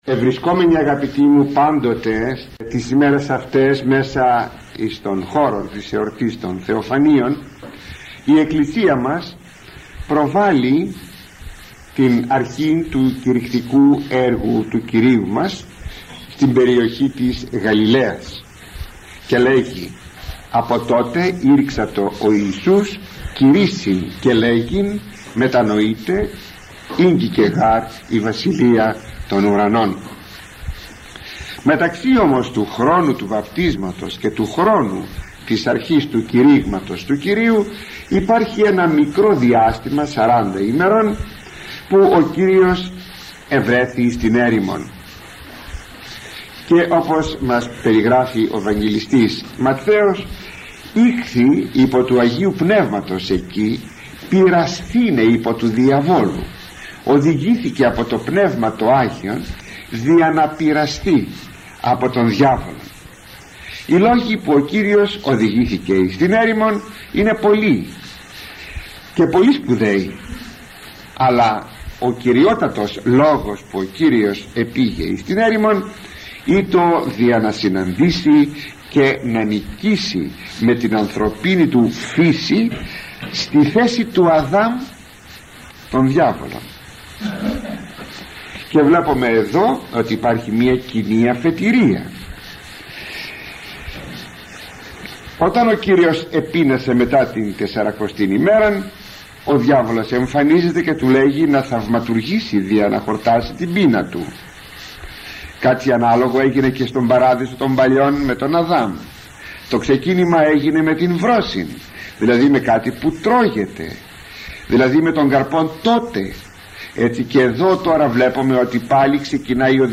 Κυριακή μετά τα Φώτα – ηχογραφημένη ομιλία του Μακαριστού Αρχιμ.